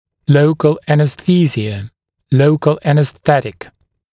[‘ləukl ˌænəs’θiːzɪə] [‘ləukl ˌænəs’θetɪk][‘лоукл ˌэнэс’си:зиэ] [‘лоукл ˌэнэс’сэтик]местная анестезия, местное обезболивание